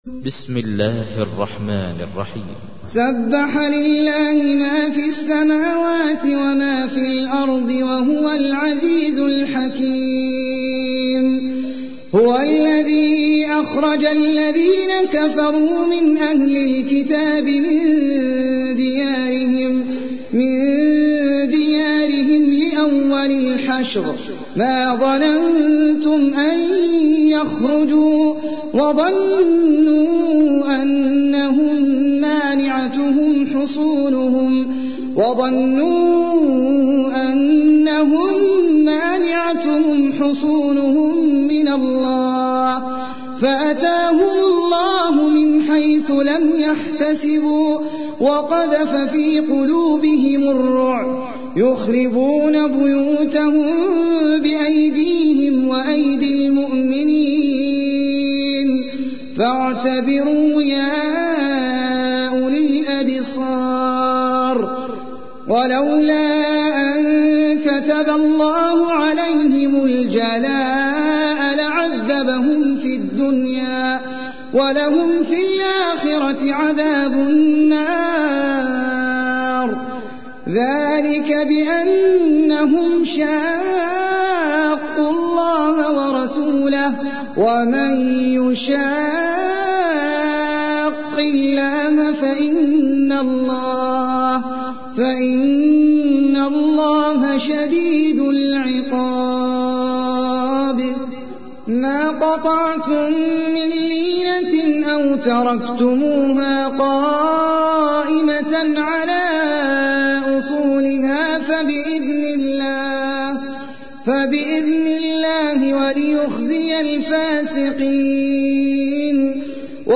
تحميل : 59. سورة الحشر / القارئ احمد العجمي / القرآن الكريم / موقع يا حسين